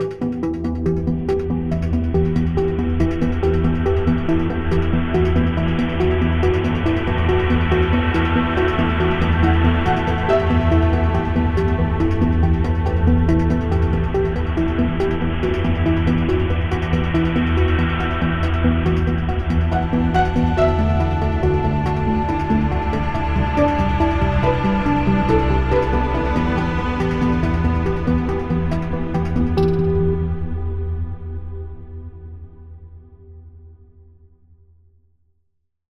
Added Ambient music pack. 2024-04-14 17:36:33 -04:00 9.9 MiB Raw Permalink History
Ambient Recollect cut 30.wav